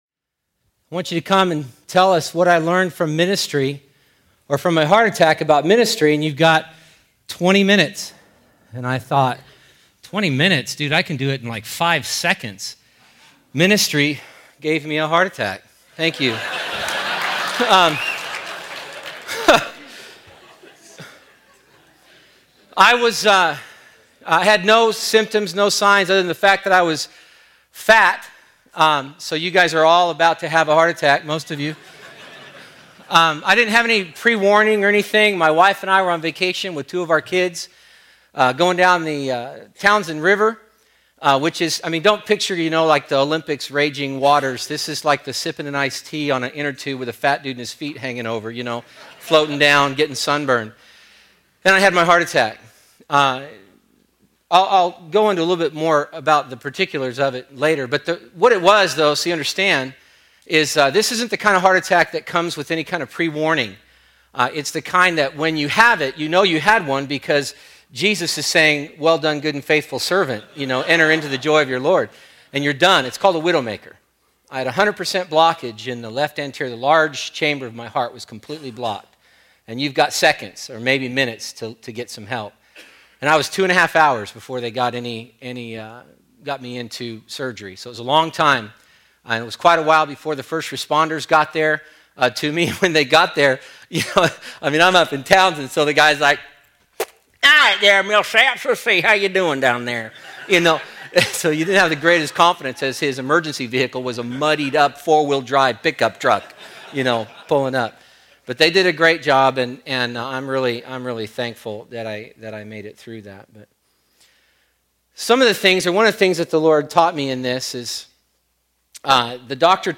2012 DSPC Conference: Pastors & Leaders Date
2012 Home » Sermons » Session 2 Share Facebook Twitter LinkedIn Email Topics